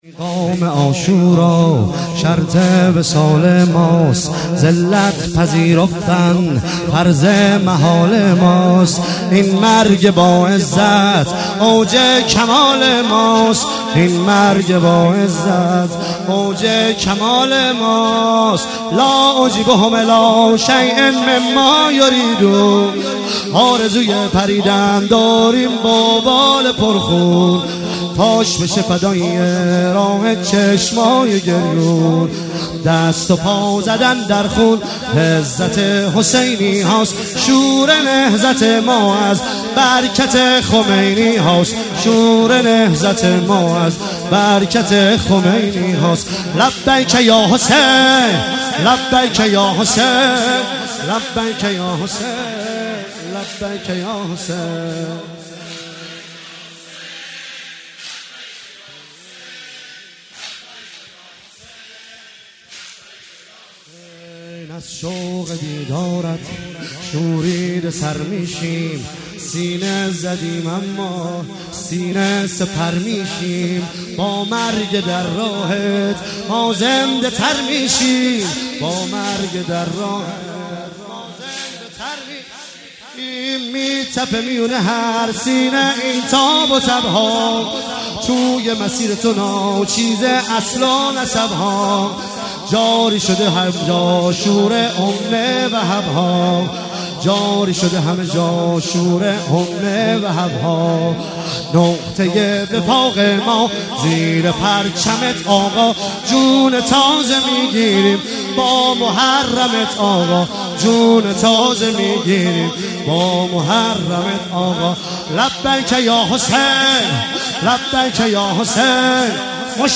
شب چهارم محرم 97 - واحد - پیغام عاشورا شرط